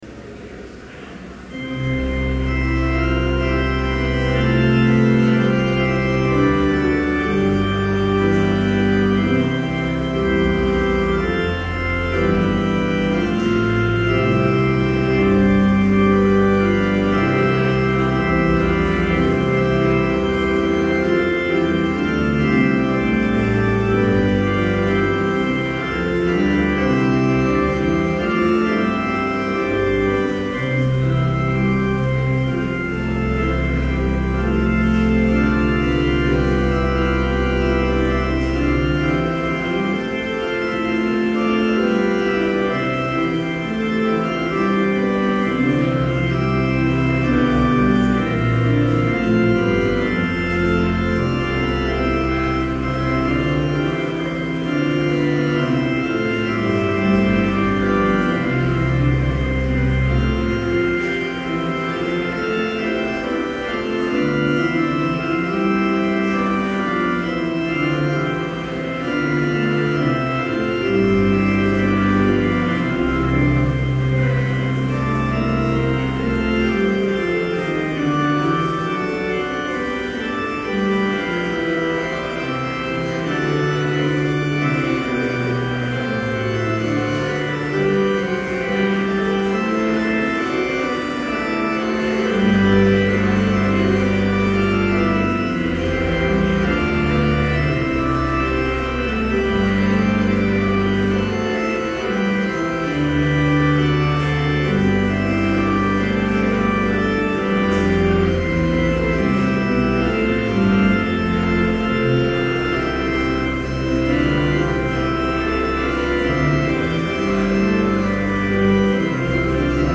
Complete service audio for Chapel - November 7, 2011
Prelude Hymn 354, vv. 1, 2 & 4, Like the Golden Sun Ascending Scripture Reading: I Thessalonians 4:13-14 Homily Prayer Hymn 354, vv. 5 & 6, Thou hast died... Benediction Postlude